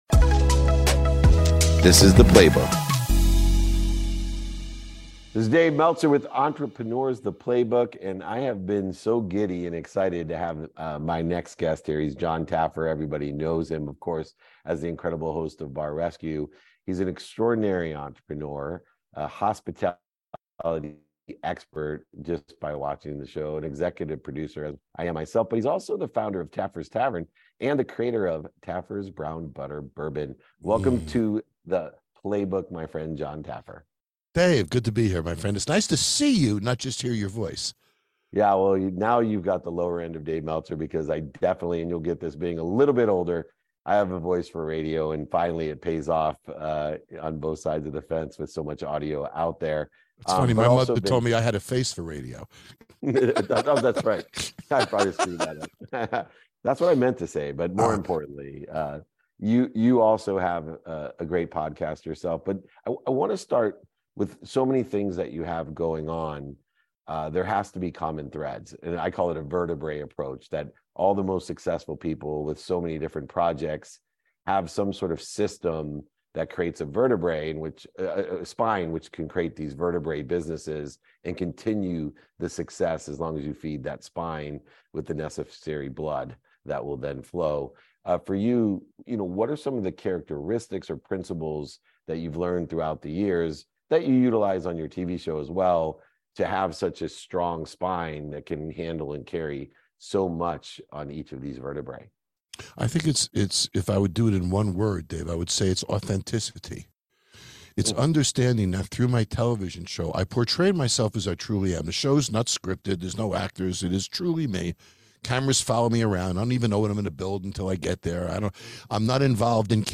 chats with Jon Taffer, an entrepreneur, hospitality expert, and star of the TV show 'Bar Rescue.' They explore how authenticity plays a pivotal role in Jon's life, acting as the backbone of his various successful endeavors. Jon explains how his unscripted, genuine approach to his TV show and businesses has propelled him to success.